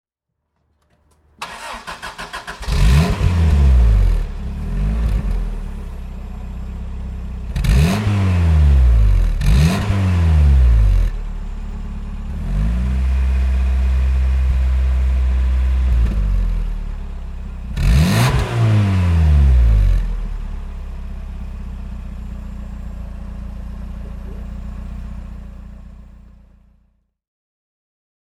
Isuzu Piazza Turbo (1990) - Starten und Leerlauf
Isuzu_Piazza_1990.mp3